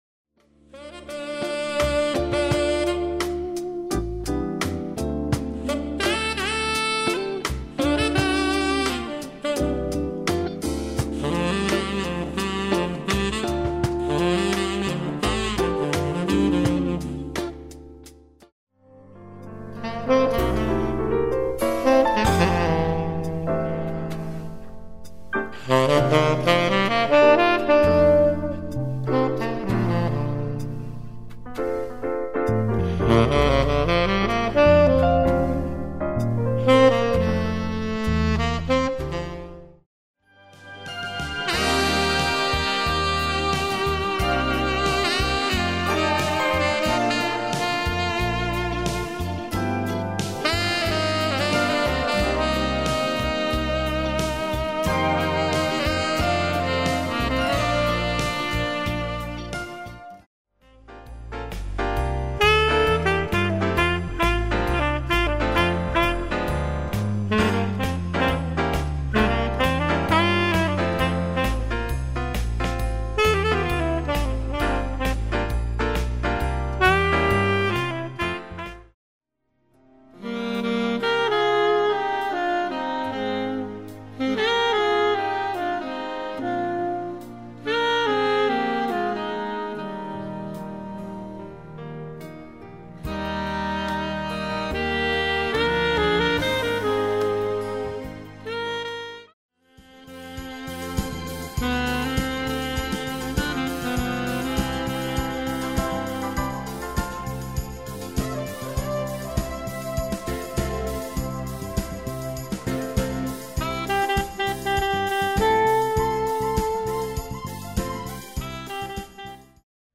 • Saxophone